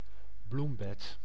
Ääntäminen
Ääntäminen Tuntematon aksentti: IPA: /raˈbat/ Haettu sana löytyi näillä lähdekielillä: ruotsi Käännös Ääninäyte 1. korting {c} 2. bed {n} 3. bloembed {n} 4. tuinbed {n} Artikkeli: en .